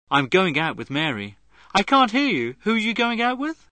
Głosu do nagrań użyczyli profesjonalni lektorzy - rodowici Brytyjczycy!